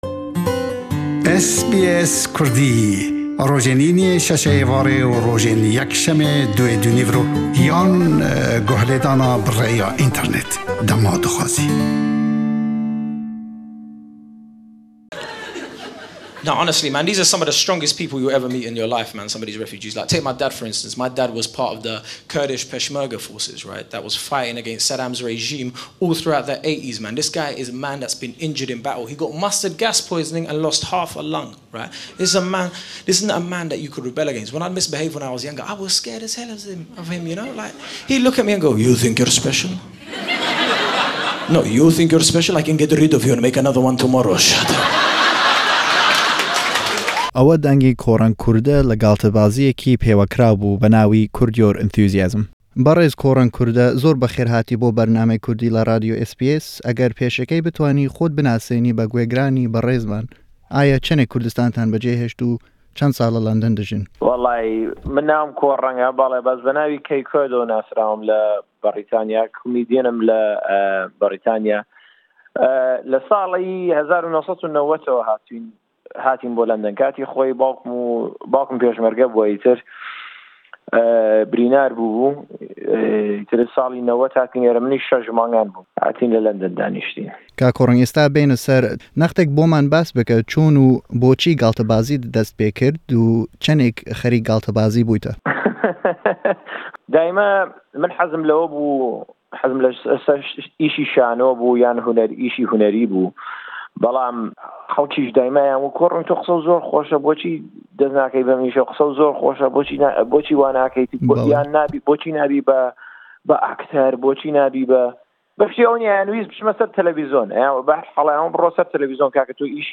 Kořeng Kurde galltebazy be pêwekraw dekat le London, nasra we be Kae Kurd. Lem lêdwane